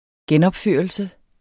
Udtale [ ˈgεnʌbˌføˀʌlsə ]